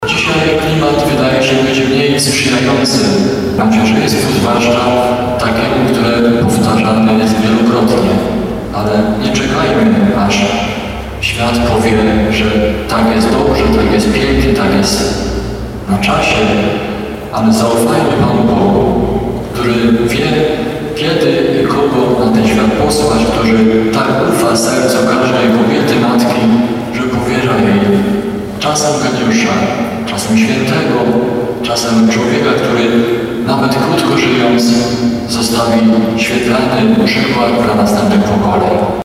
Mszy św. przewodniczył biskup Stanisław Salaterski, który w homilii mówi o szacunku dla ludzkiego życia.
9kazanie.mp3